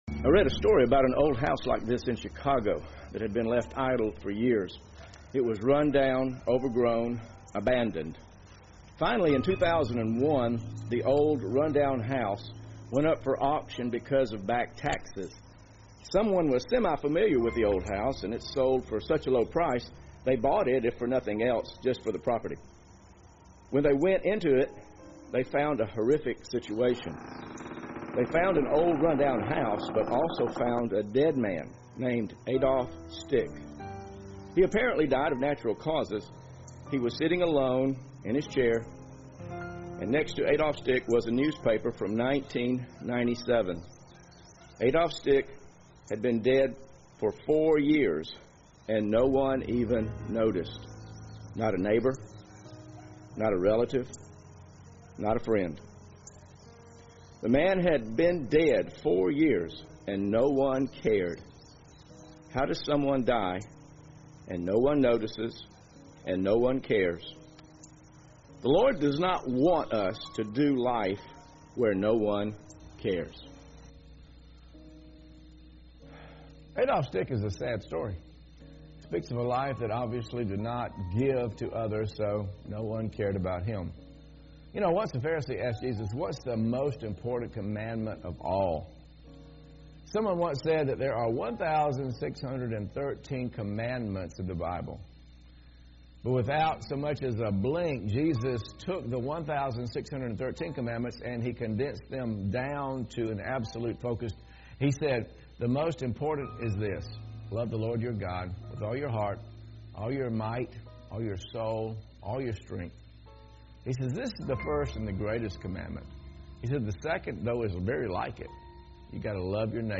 From House to House: How to Cultivate Genuine Fellowship sermon audio video notes.